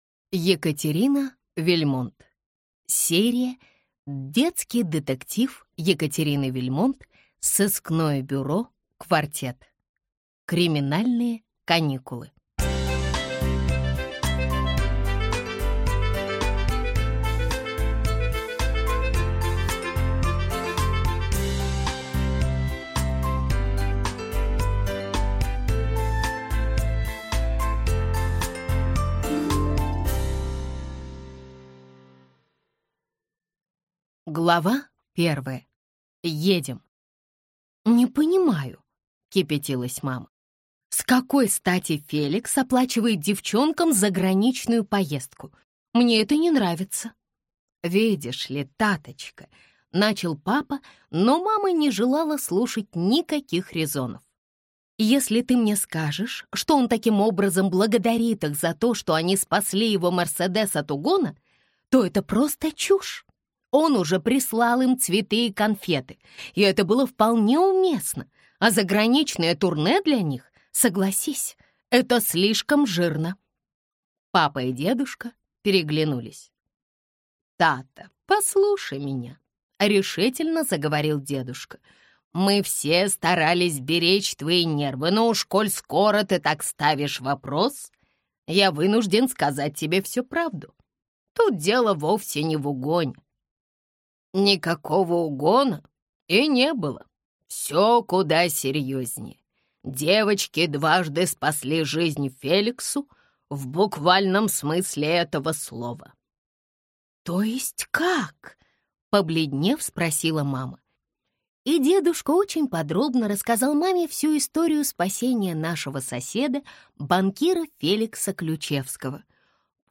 Аудиокнига Криминальные каникулы | Библиотека аудиокниг